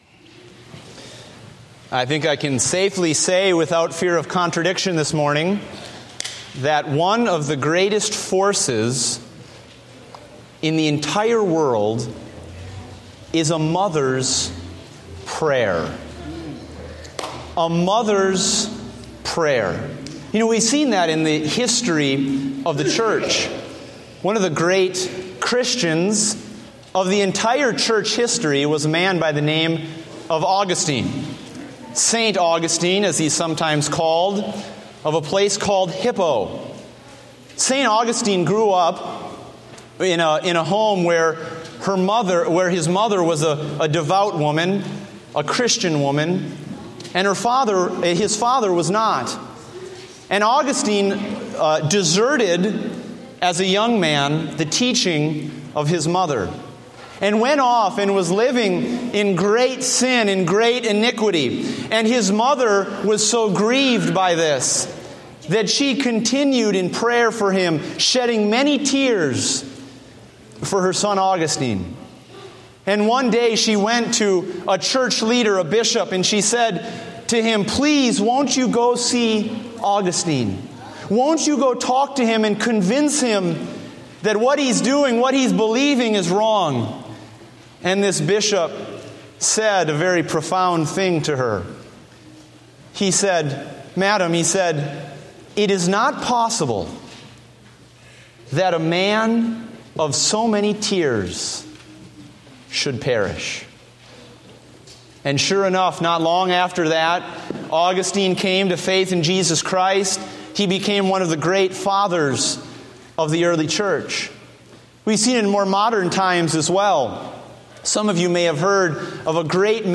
Date: May 10, 2015 (Morning Service)